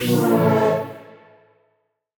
Index of /musicradar/future-rave-samples/Poly Chord Hits/Ramp Down
FR_T-PAD[dwn]-A.wav